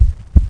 coracao.mp3